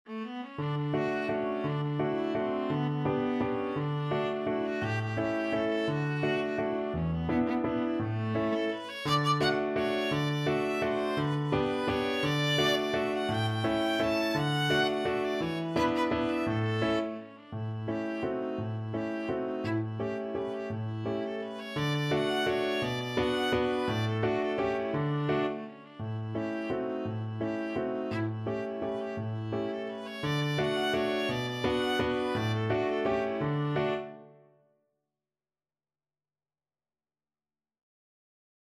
Viola
3/4 (View more 3/4 Music)
D major (Sounding Pitch) (View more D major Music for Viola )
Classical (View more Classical Viola Music)